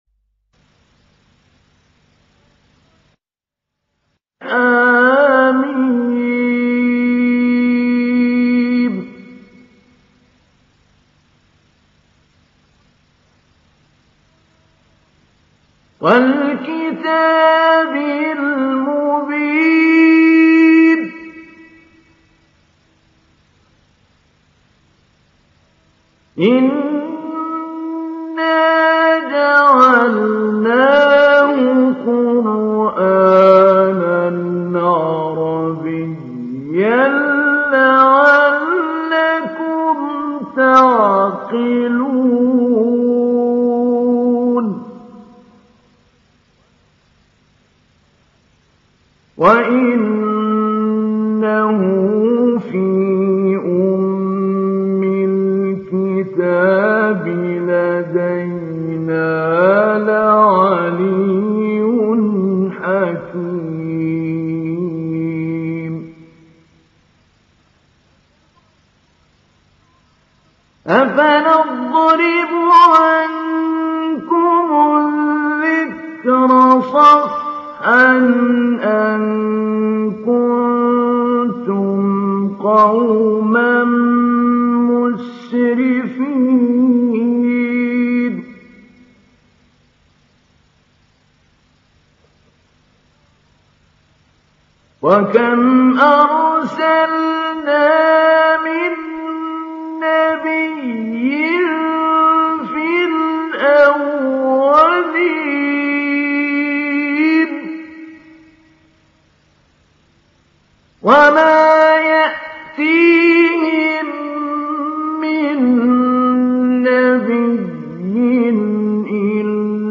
İndir Zuhruf Suresi Mahmoud Ali Albanna Mujawwad